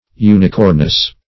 Search Result for " unicornous" : The Collaborative International Dictionary of English v.0.48: Unicornous \U`ni*cor"nous\, a. [See Unicorn .]